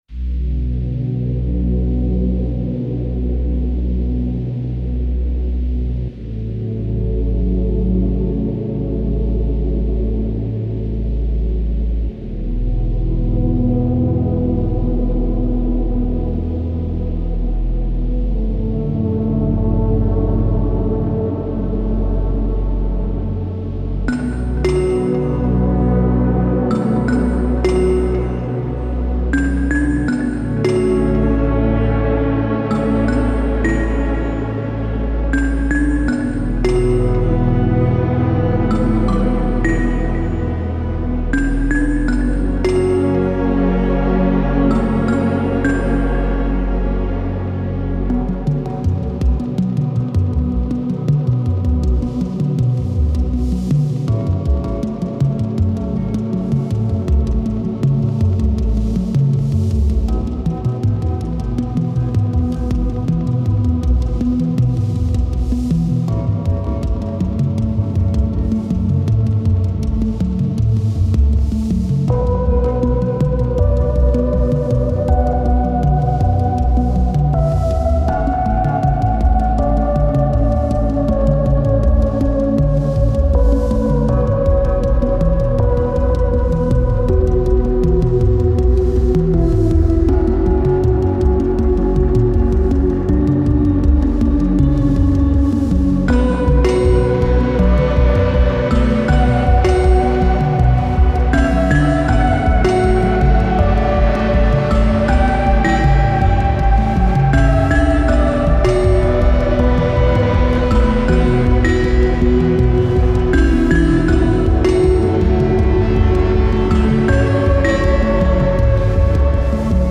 An ominous rumble swells from deep within.